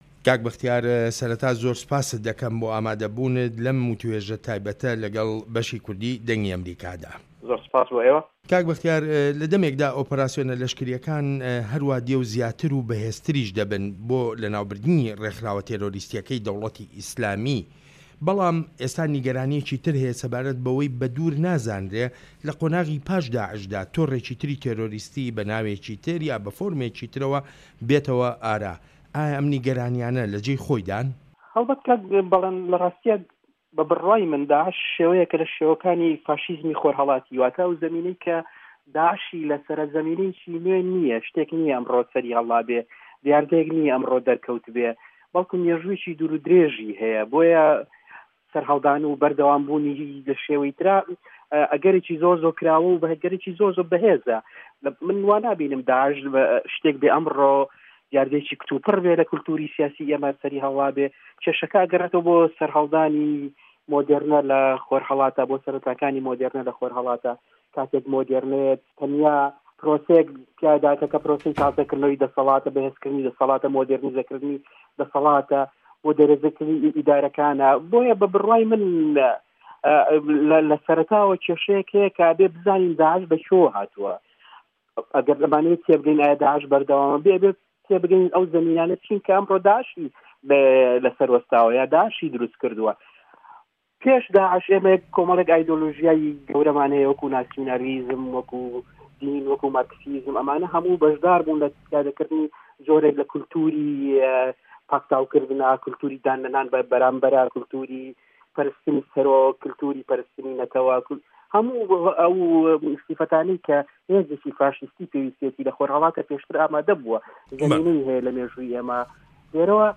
(بەختیار عەلی) نوسەر و ڕوناکبیر لە وتووێژێکی تایبەتدا لەگەڵ بەشی کوردی دەنگی ئەمریکادا، باس لە ژمارەیەک دۆسێی گرنگ و هەستیاری ڕۆژ دەکات وەک شەڕی دژی تێرۆریستانی دەوڵەتی ئیسلامی و هەروەهاش قەیرانەکانی هەرێمی کوردستانی عێراق.
وتووێژ لەگەڵ بەختیار عەلی